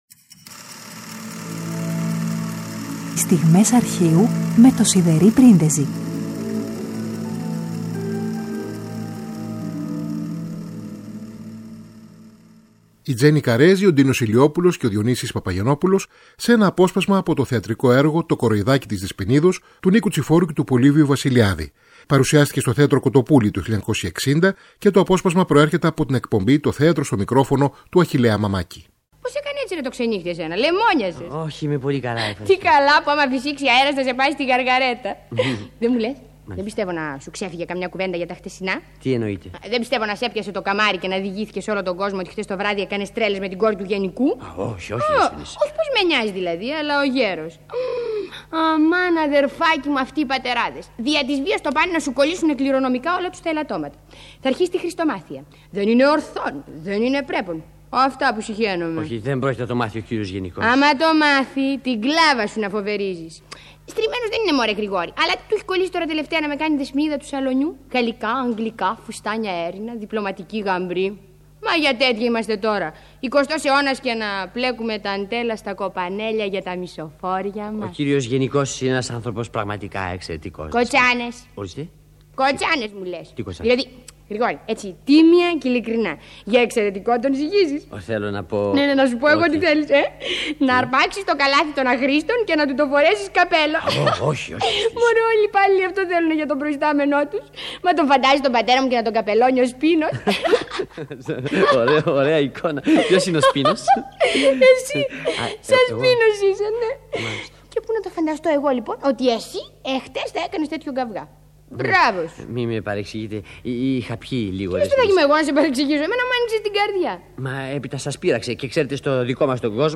Με αφορμή τα 70 χρόνια του Δεύτερου, που συμπληρώνονται το 2022, ακούμε μοναδικά ντοκουμέντα από το Αρχείο της Ελληνικής Ραδιοφωνίας, με τίτλο «Στιγμές Αρχείου»